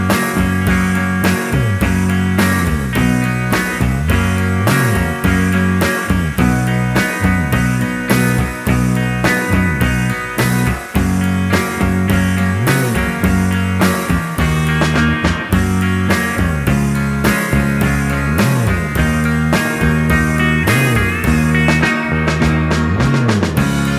No Harmony Pop (1960s) 3:06 Buy £1.50